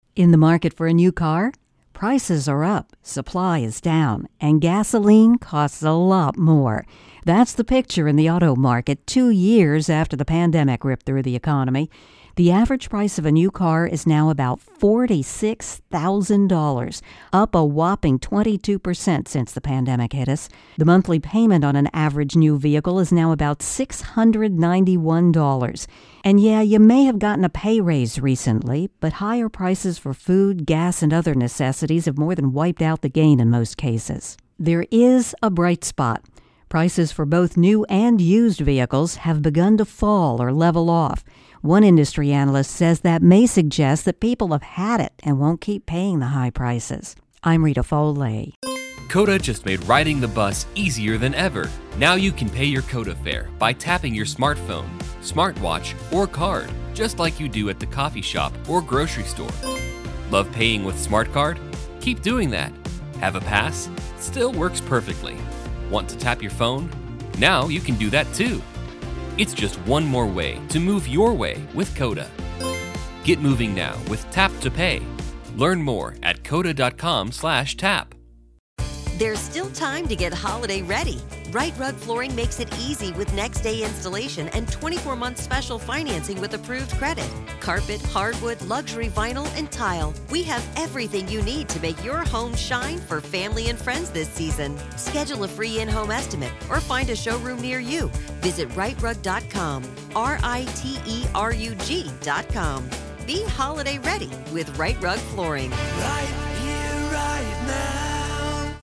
Auto Market Intro+Voicer